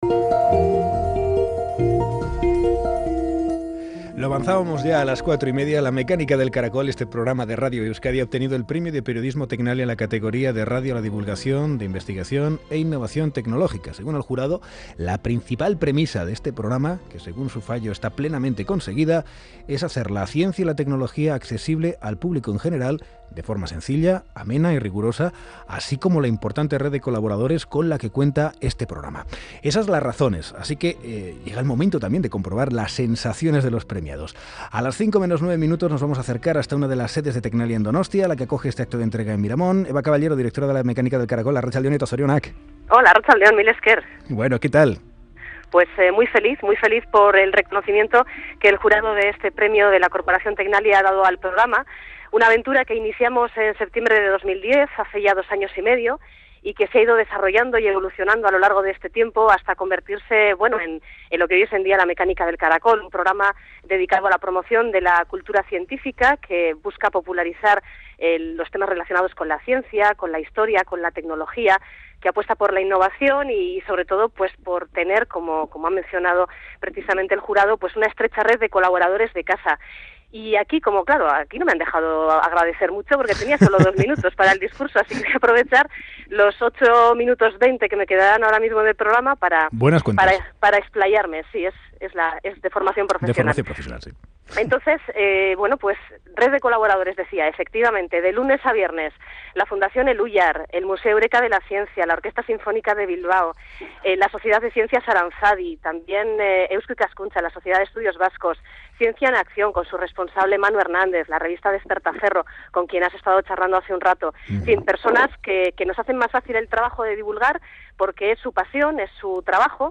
Premio Tecnalia 2012 | La Mecánica del Caracol | Directo